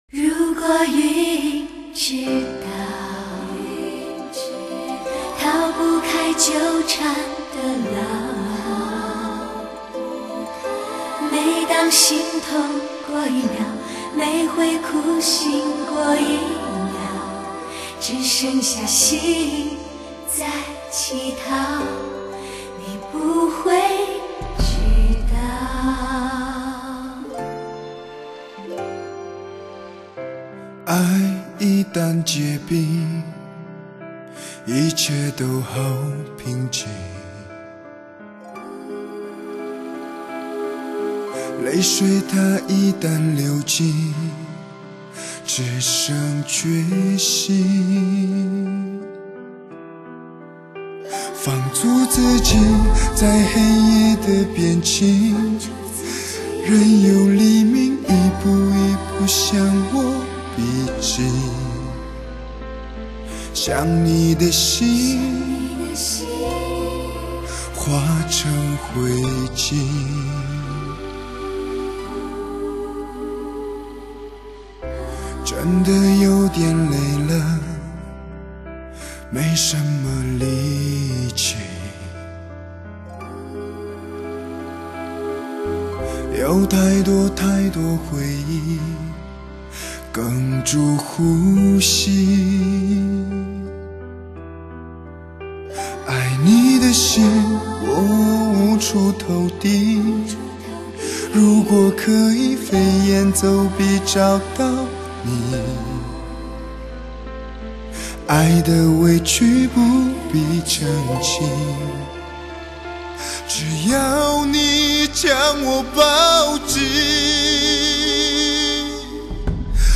专为汽车音响录制的音效精品